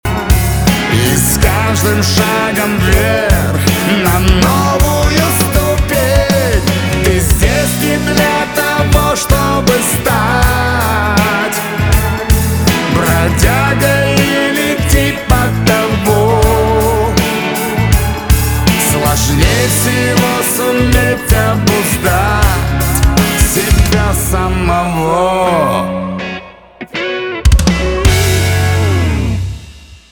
поп
чувственные , барабаны , гитара